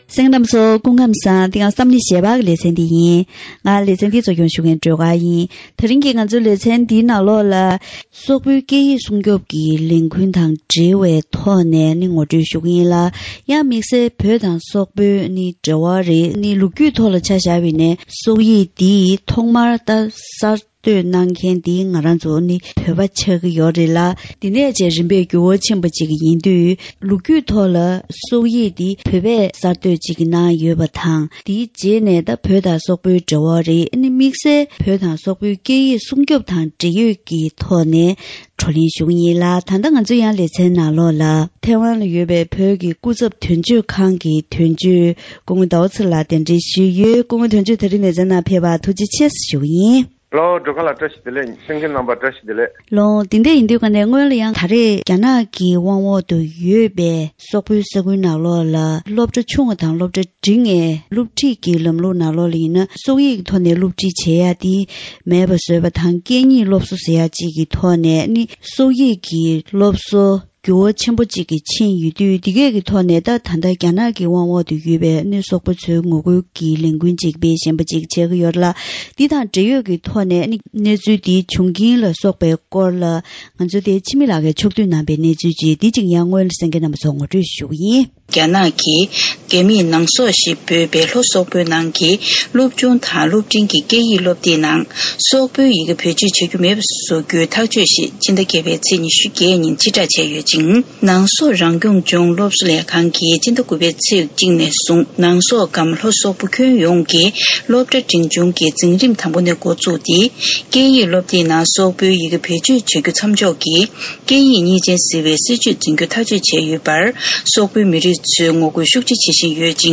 ཐེངས་འདིའི་གཏམ་གླེང་ཞལ་པར་ལེ་ཚན་ནང་ཉེ་ཆར་རྒྱ་ནག་གི་དབང་བསྒྱུར་འོག་ཡོད་པའི་སོག་ཡུལ་དུ་སོག་སྐད་ཀྱི་སློབ་གསོ་ཉུང་དུ་གཏོང་རྒྱུའི་སྲིད་ཇུས་ཐོག་ནས་ཐོན་པའི་སྐད་ཡིག་སྲུང་སྐྱོབ་ཀྱི་ལས་འགུལ་དང་།